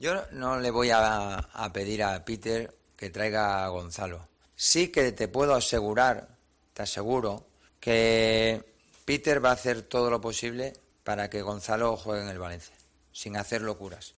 El entrenador del equipo che habló del posible retorno de Gonzalo Guedes al Valencia en la rueda de prensa previa al partido frente al Espanyol.